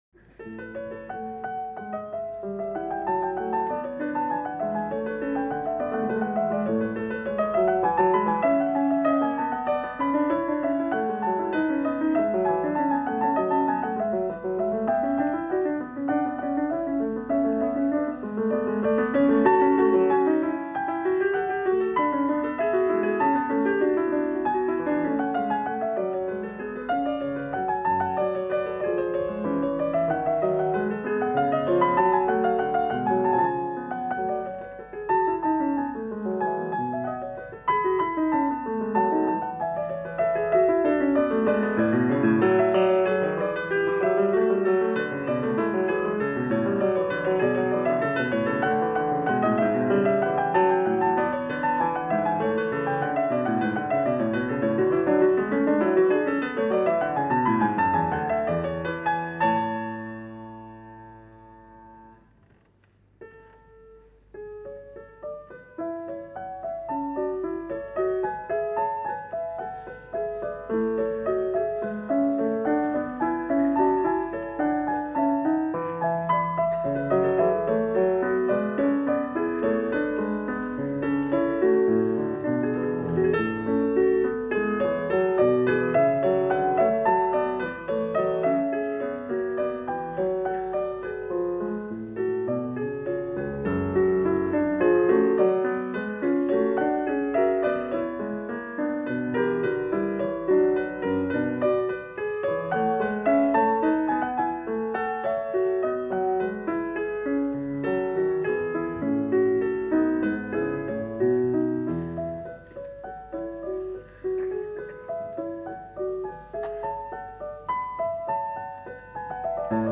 Будапешт, 10.03.1954 (live)